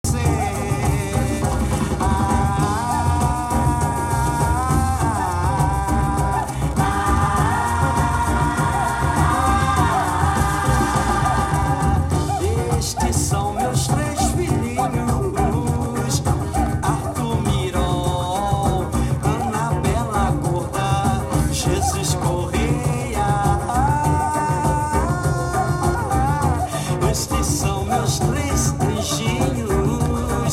Gênero: Disco, Funk, Latin, MPB, Soul
com a reprodução original do disco anunciado: